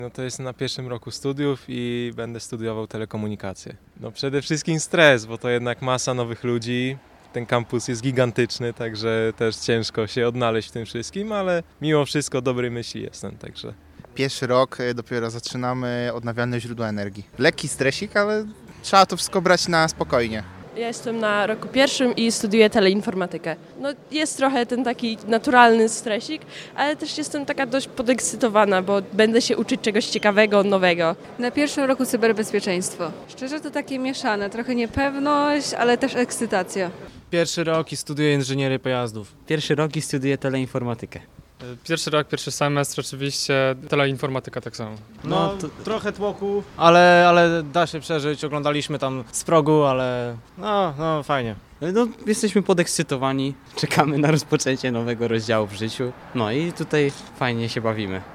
Na uroczystą inaugurację licznie przybyli studenci pierwszego roku. Jeszcze przed wejściem na aulę opowiedzieli co będą studiować, a także jakie są ich myśli i uczucia przed rozpoczęciem nowego etapu życia.